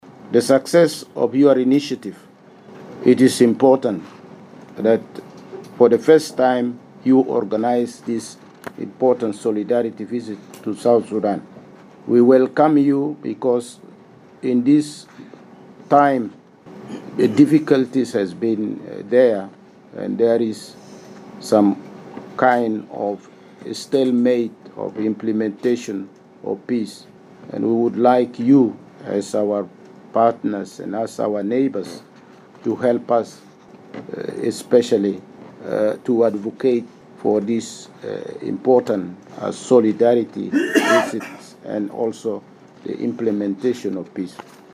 The Catholic prelates were speaking during the opening of a three-day meeting at the Sudan and South Sudan Bishops’ Conference in Juba.